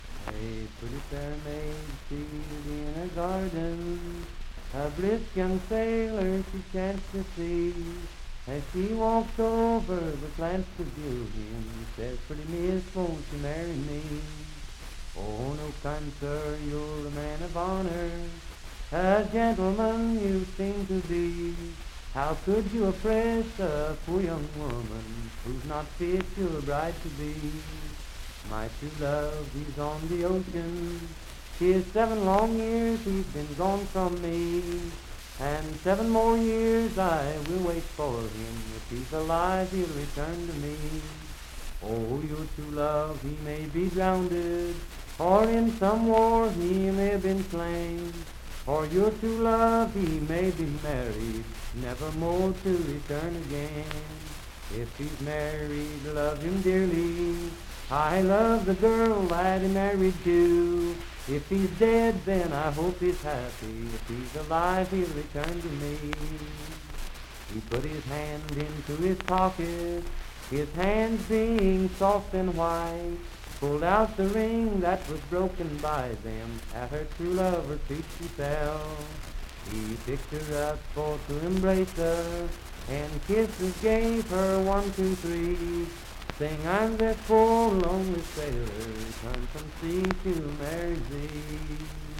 Unaccompanied vocal music
Verse-refrain 7(4).
Voice (sung)
Wood County (W. Va.), Parkersburg (W. Va.)